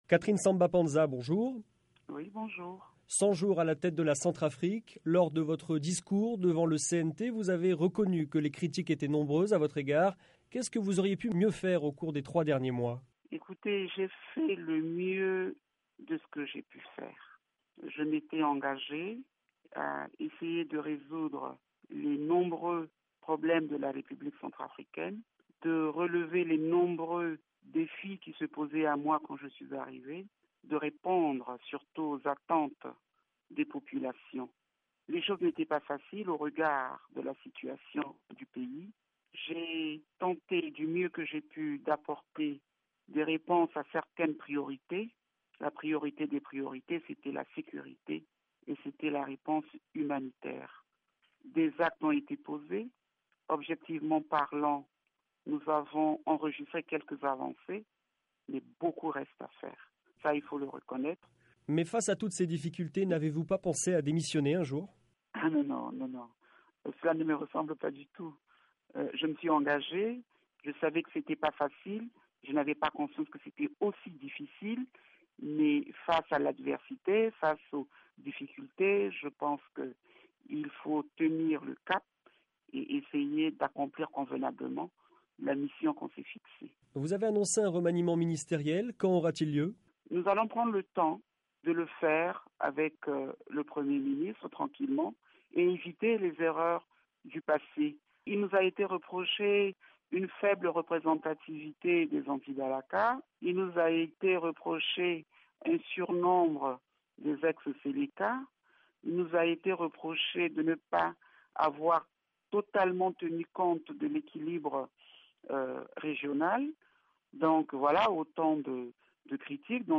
Au lendemain de son discours prononcé devant les membres du CNT, la présidente centrafricaine est l’invitée ce soir de la Voix de l’Amérique. Catherine Samba-Panza estime avoir fait ce qu’elle a pu, cent jours après avoir été nommée à la tête du pays. Concernant le prochain gouvernement, une place plus grande devrait être accordée aux anti-balaka, selon Mme Samba-Panza.